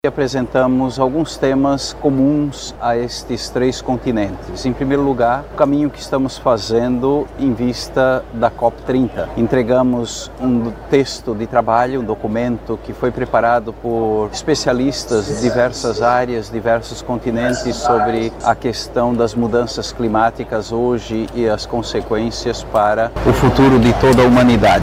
Sonora-1-–-Dom-Jaime-Spengler-.mp3